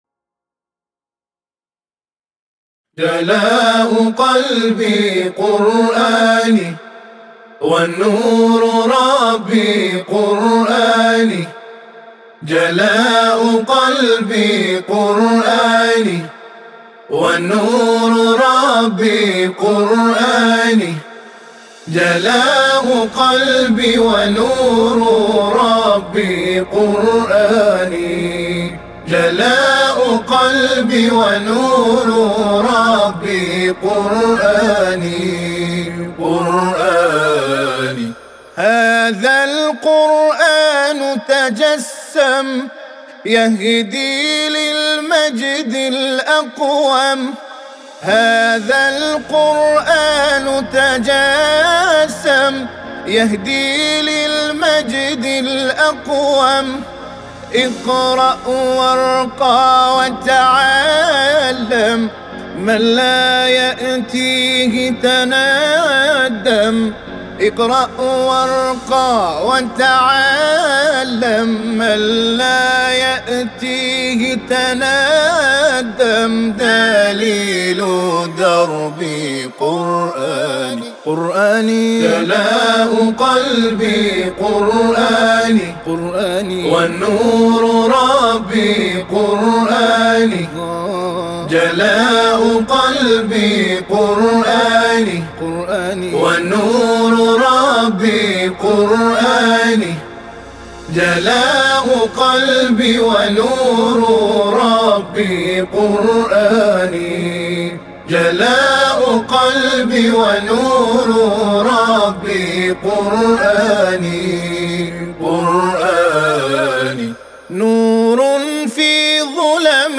نغمه‌ای دینی
انشاد دینی